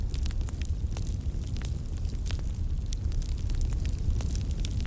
FireIdleLoop.ogg